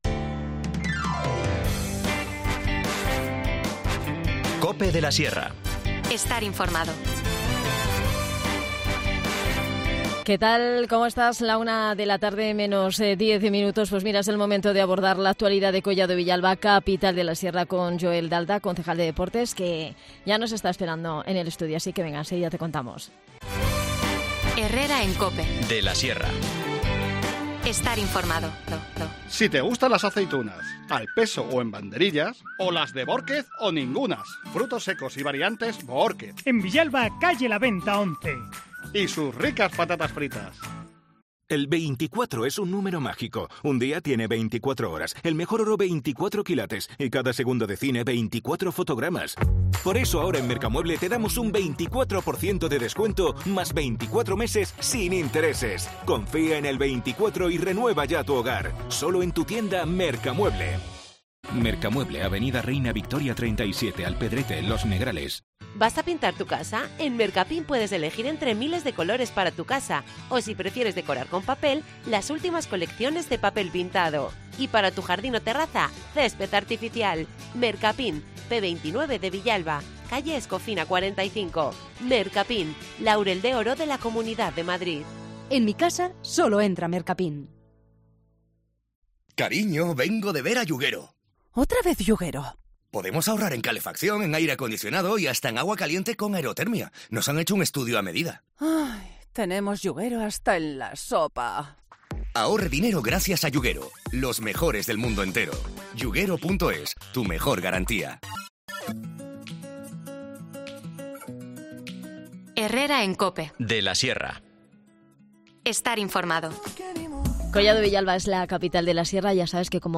Es una de las cuestiones que hemos abordado con el concejal de Deportes, Joel Dalda.